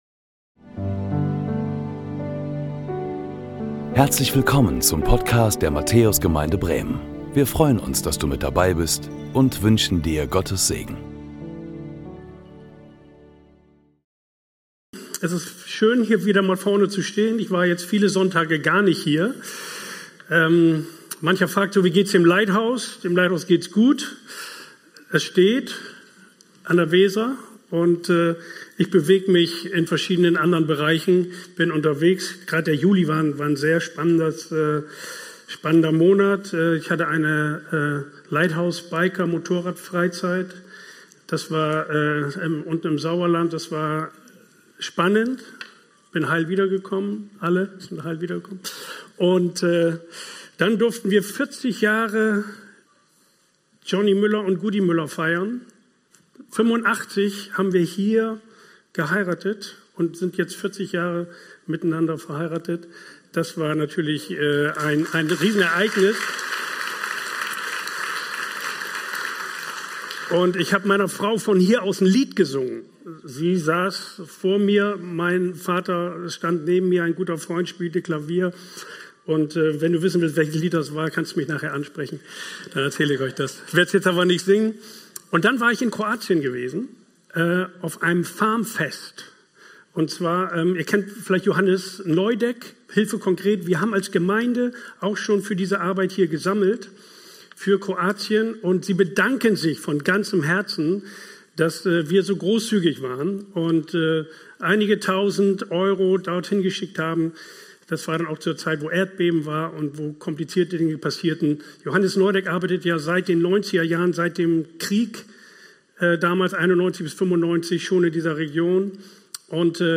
Predigten der Matthäus Gemeinde Bremen Lebensstil Ermutigung Play Episode Pause Episode Mute/Unmute Episode Rewind 10 Seconds 1x Fast Forward 30 seconds 00:00 / 40:17 Abonnieren Teilen Apple Podcasts RSS Spotify RSS Feed Teilen Link Embed